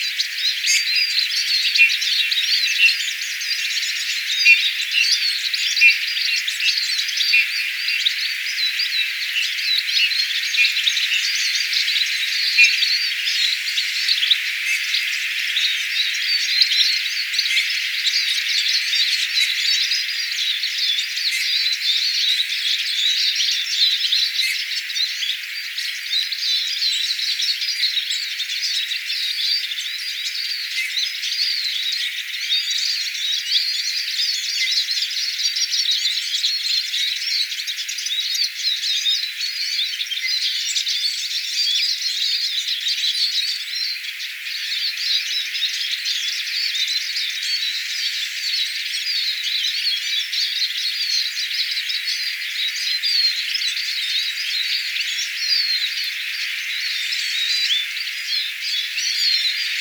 vihervarpuset laulavat innokkaasti,
kuuluu tiklin ääntelyä
vihervarpuset_laulavat_linturuokinnalla_tikli_aantelee.mp3